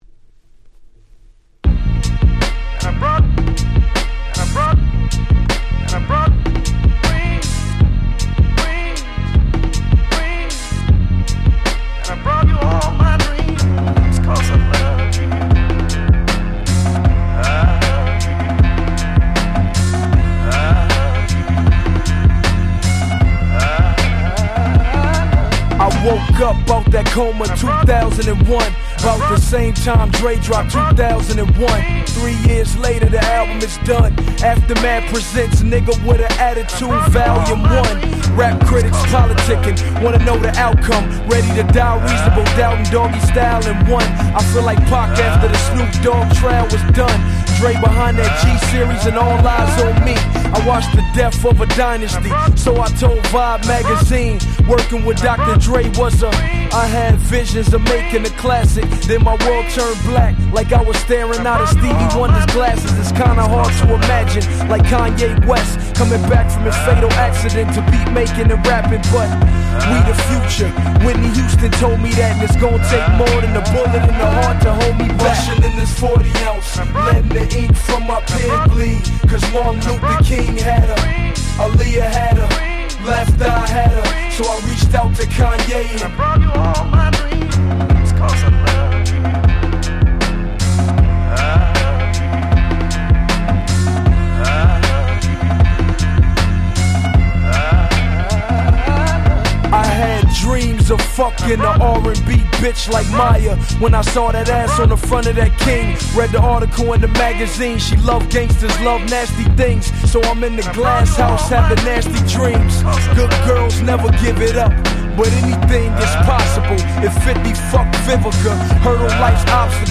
05' Big Hit Hip Hop !!